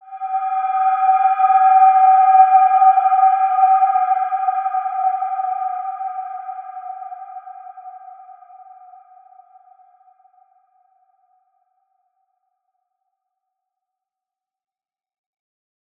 Wide-Dimension-G4-mf.wav